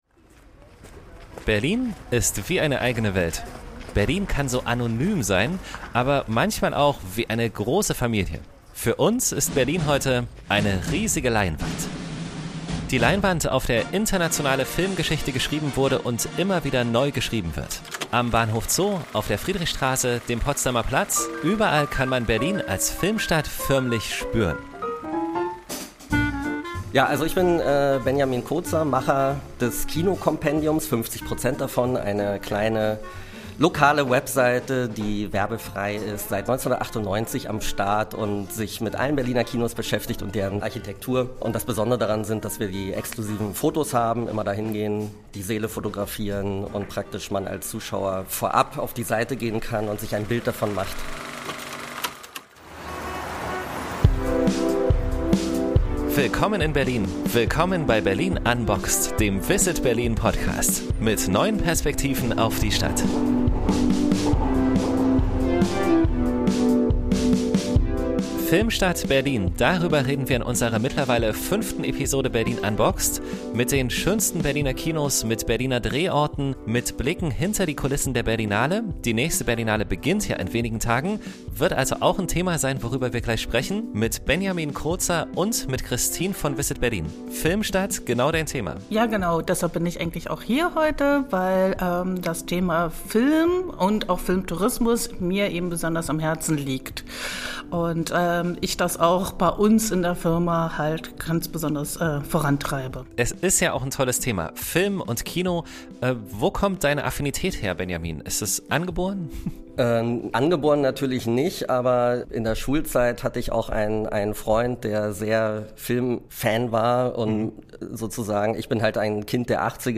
In diesen Tagen schaut die Filmwelt zum 72. Mal auf unsere Stadt und damit aufs größte Publikumsfestival der Welt. Passend dazu sprechen wir in dieser Episode mit